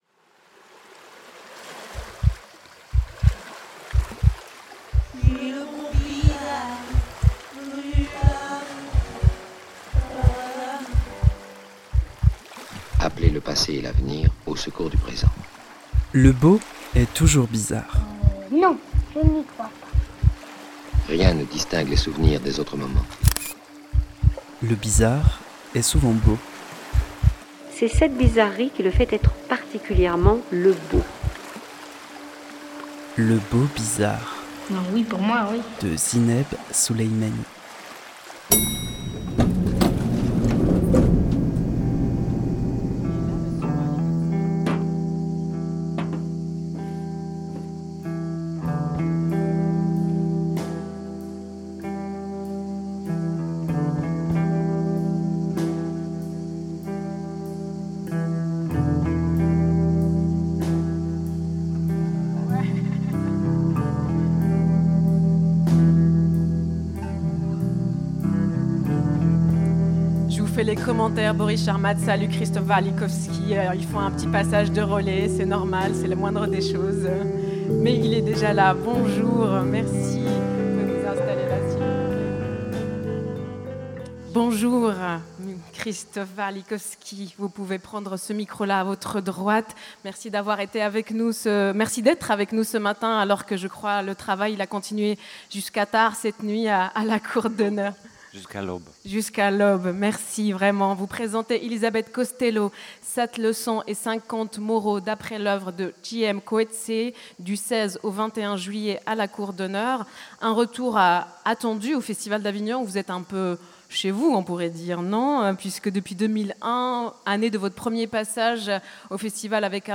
hors-serie-krzysztof-warlikowski-au-festival-davignon.mp3